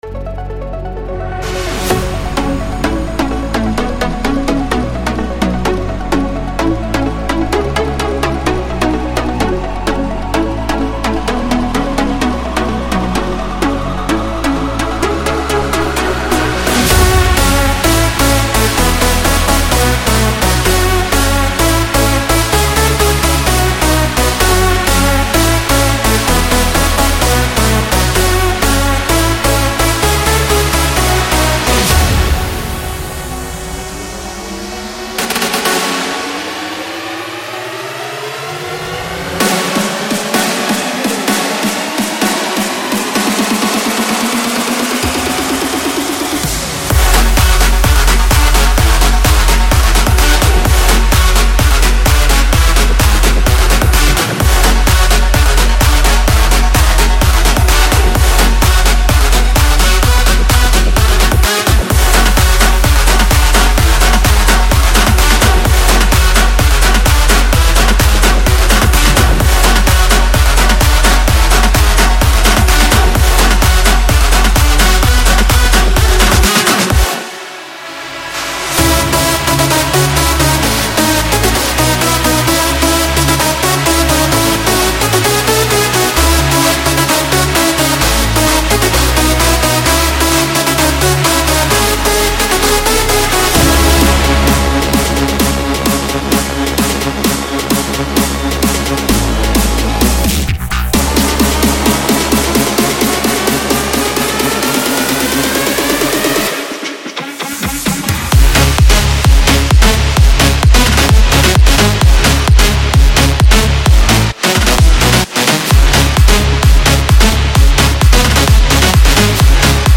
回到为 Bigroom 和 EDM 黄金时代增光添彩的巨大欣快声音的根源。
期待找到熟悉的庞然大物合成即兴重复段、史诗般的打击乐主奏、雷鸣般的踢腿等等……